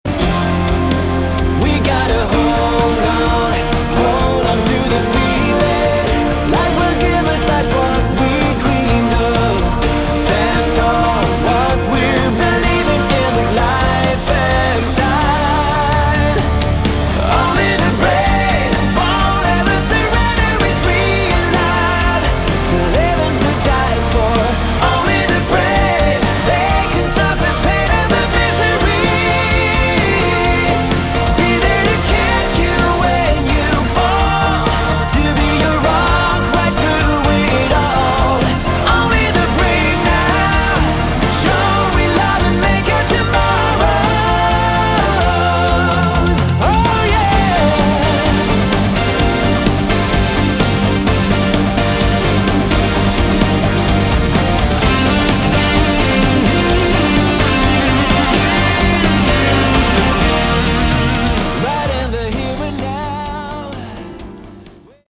Category: Melodic Rock
lead & backing vocals
guitars
piano & keyboards
bass
drums
Uplifting and extremely melodic as you'd expect.